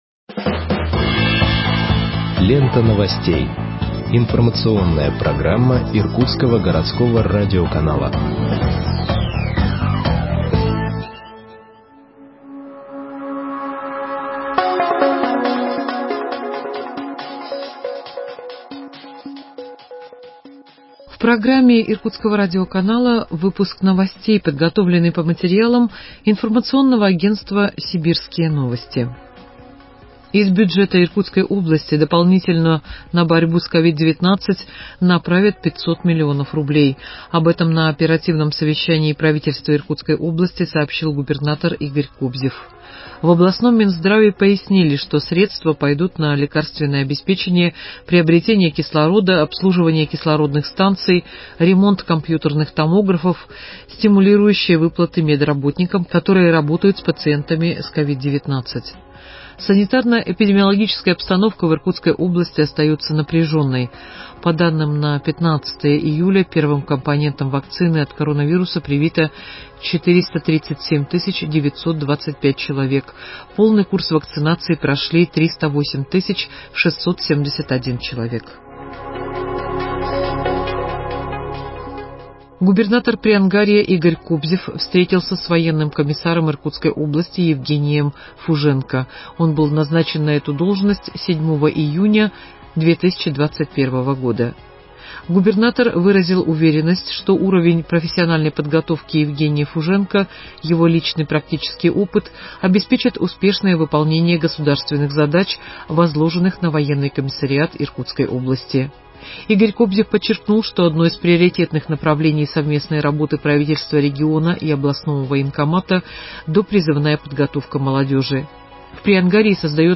Выпуск новостей в подкастах газеты Иркутск от 19.07.2021 № 1